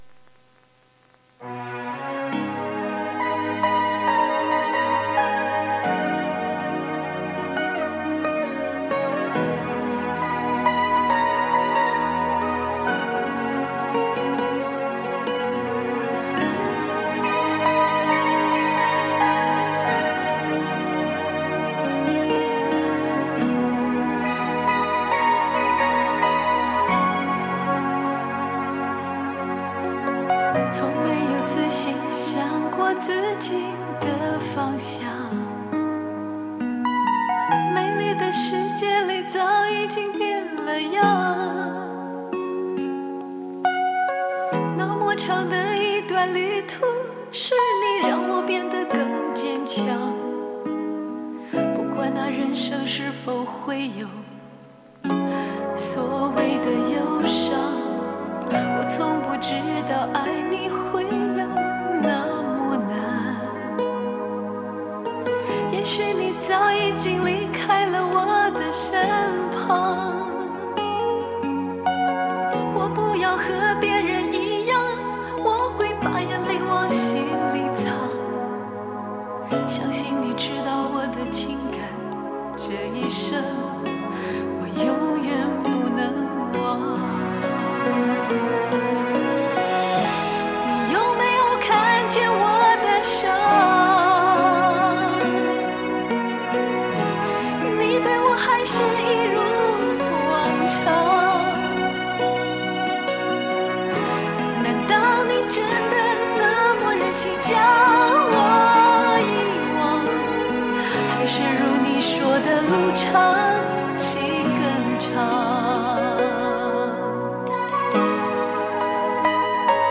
磁带数字化：2022-08-14